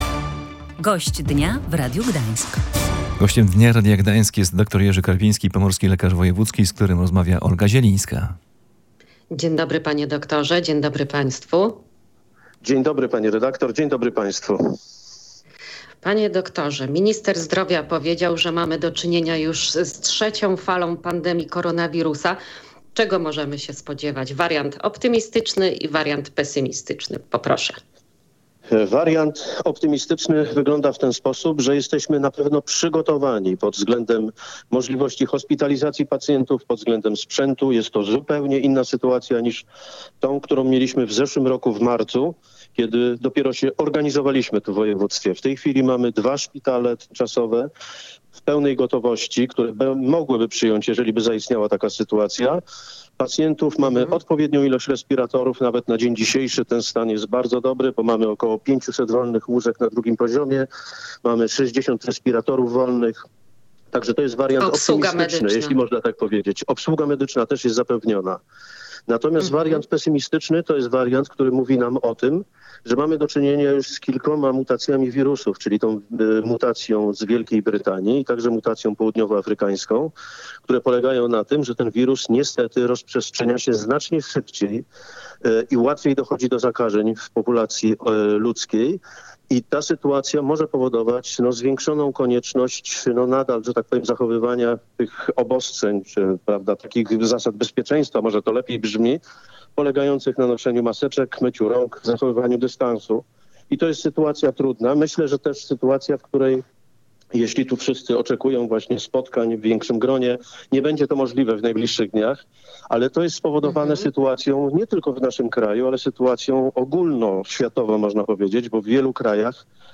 W audycji „Gość Dnia Radia Gdańsk” do tych zapowiedzi odniósł się dyrektor wydziału zdrowia w Pomorskim Urzędzie Wojewódzkim, dr Jerzy Karpiński.